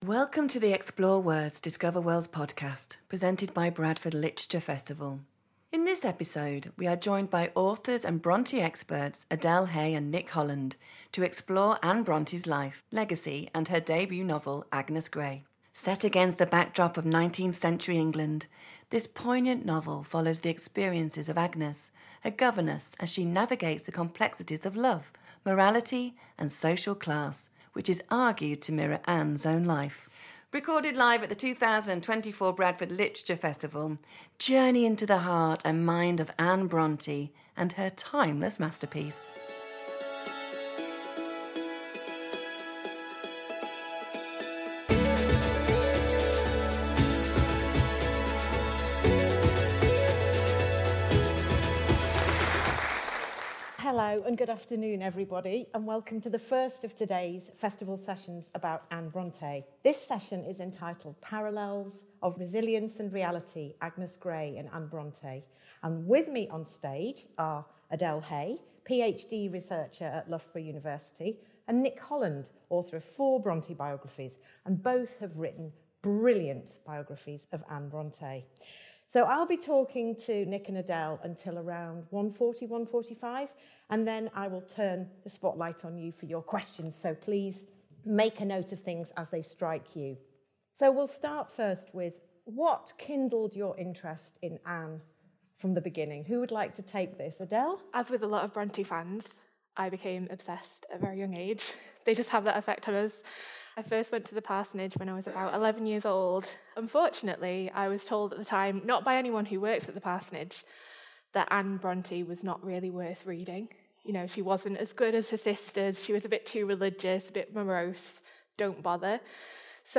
we are joined by authors and Brontë experts